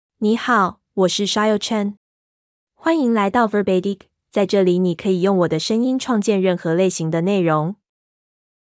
FemaleChinese (Taiwanese Mandarin, Traditional)
Voice sample
Female